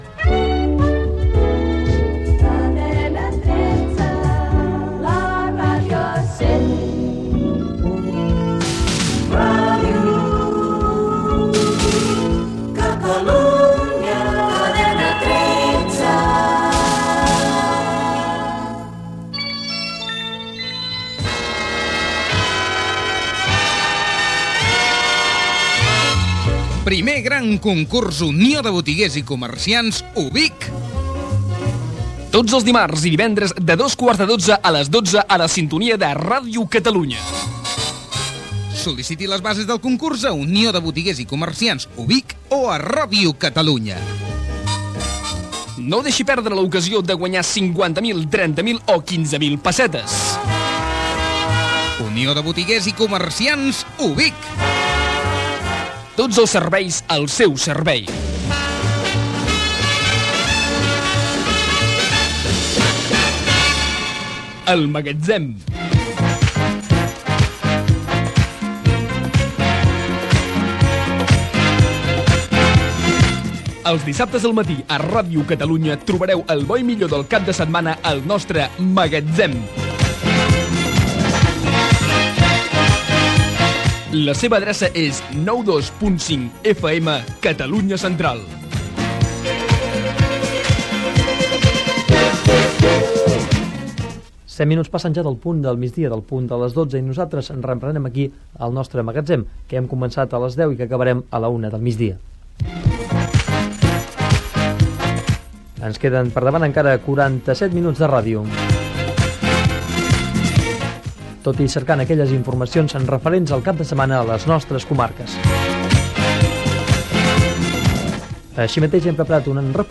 Indicatiu Cadena 13, indicatiu Ràdio Catalunya, publicitat i continguts del programa.